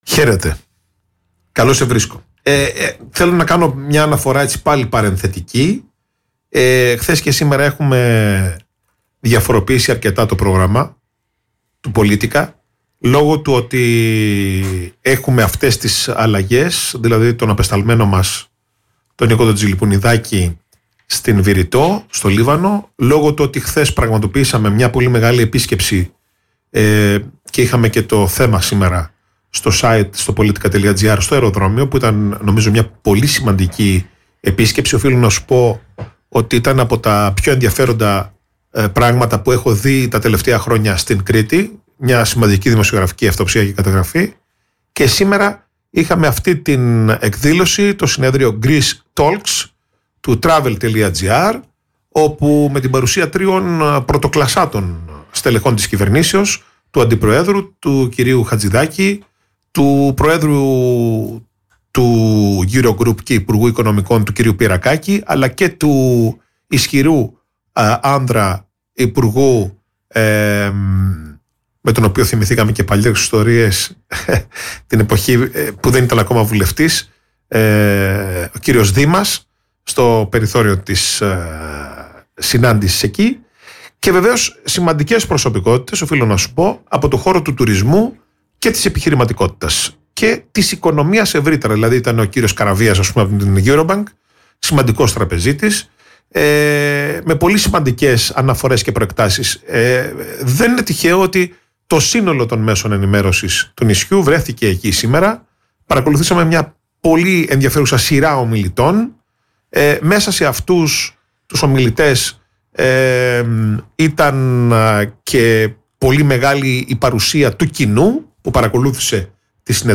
απόσπασμα της ραδιοφωνικής κουβέντας